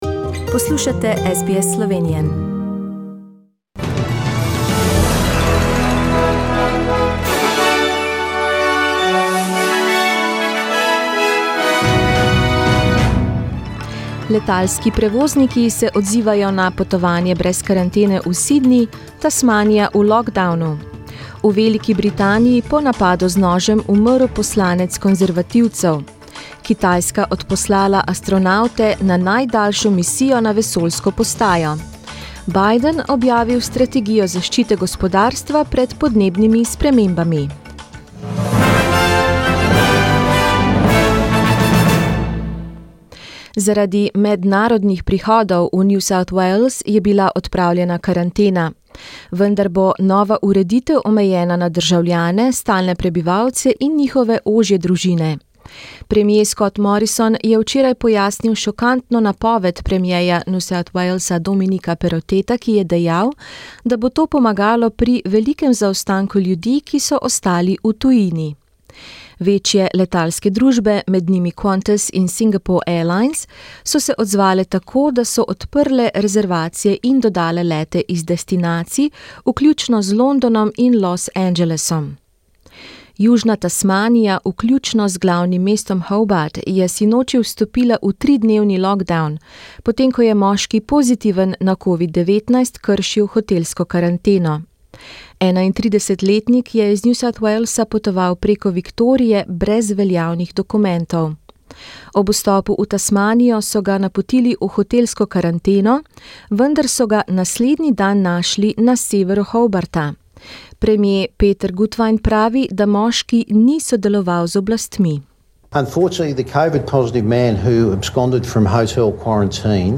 Poročila iz Avstralije in sveta v slovenskem jeziku 16.oktobra